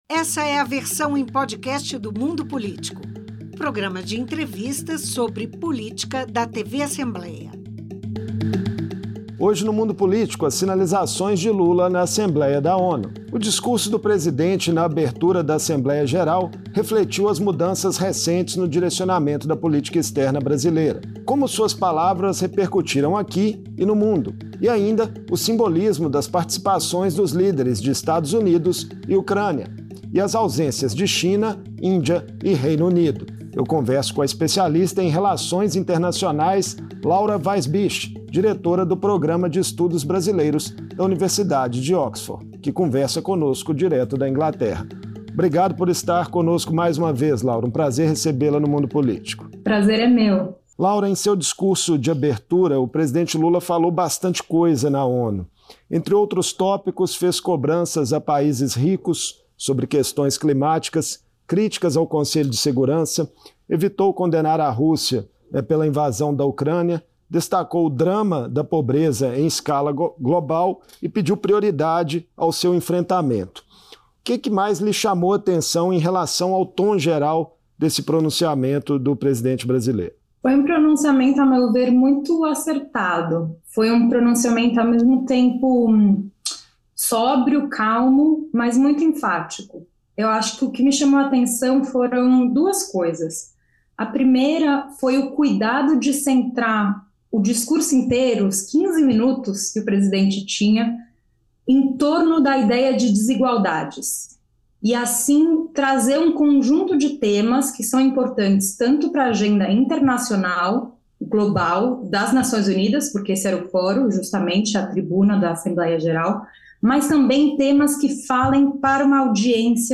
O tema desigualdade sob várias perspectivas permeou o discurso do presidente Lula na Assembleia Geral da ONU, o que despertou reações positivas dentro e fora do Brasil. Em entrevista